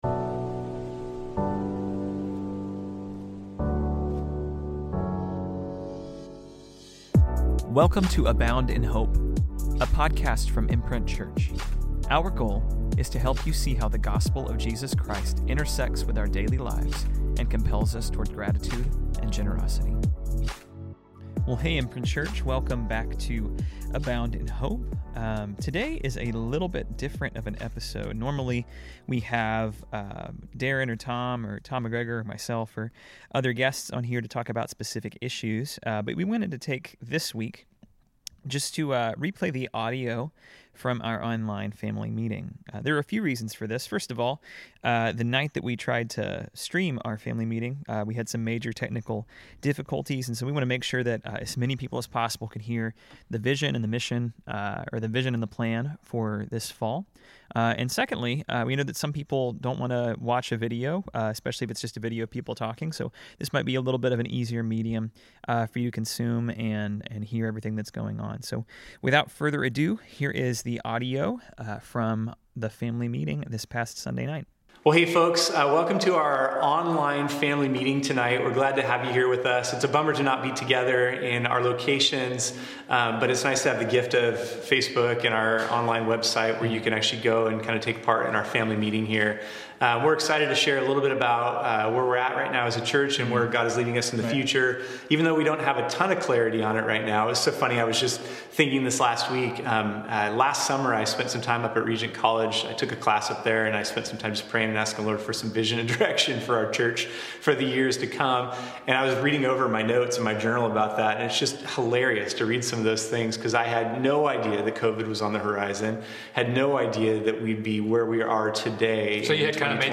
On this special episode of Abound in Hope, we're replaying the audio from our online family meeting on September 6th.